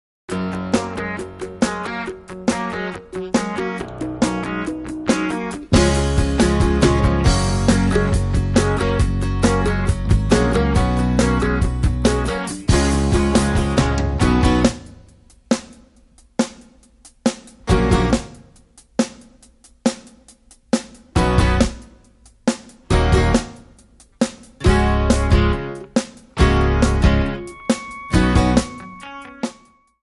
Backing track files: 1990s (2737)
Buy With Backing Vocals.